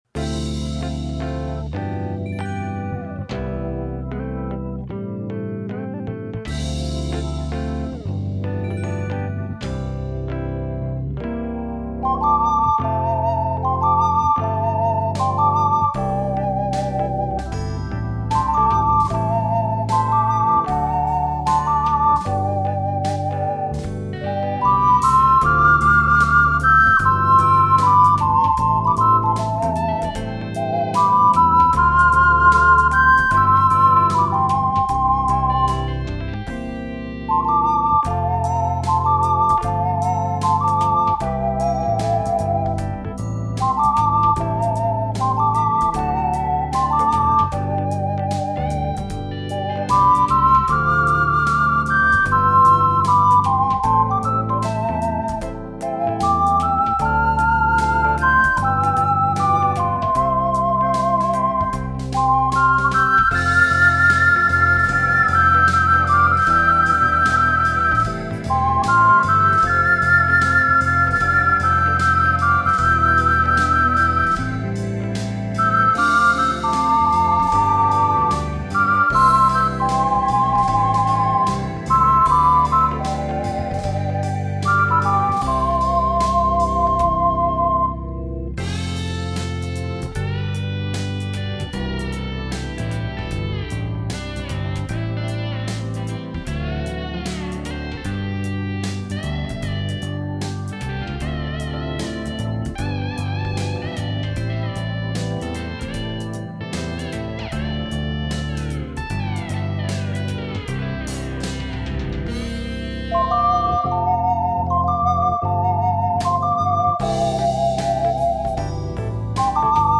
＊試奏は雑に録音・編集したので、参考程度と思ってください。